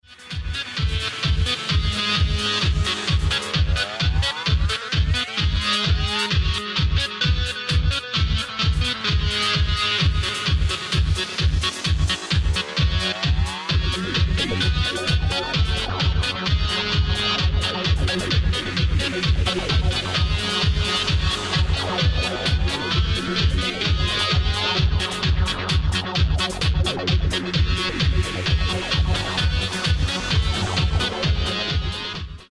Ultimate hardstyle remix